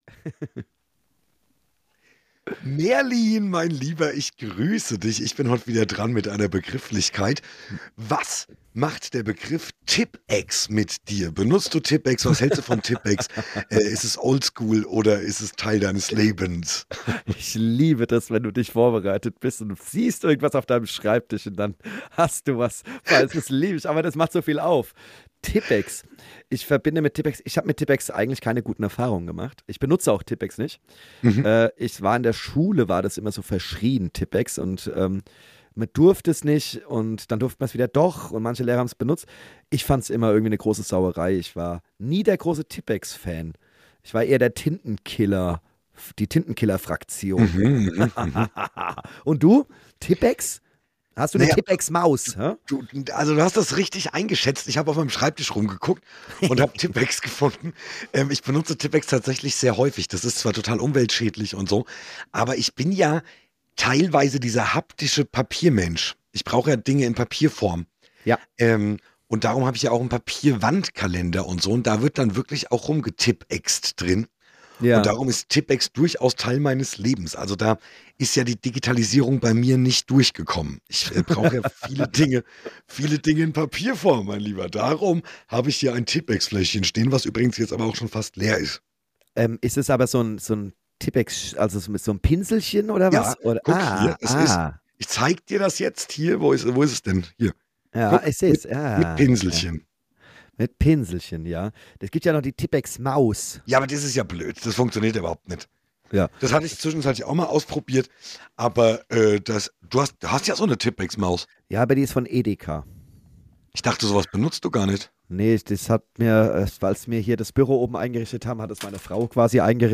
Abschließend philosophieren sie über die Zeit und wie schnell das Jahr vergangen ist. Ein Gespräch am Montag Abend - vor der US-Wahl und soweiter... Wie immer ungeschnitten, roh und voller Elan.